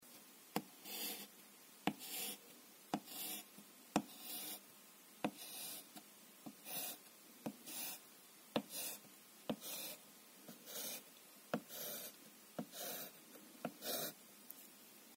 Рисуют мелом линии на доске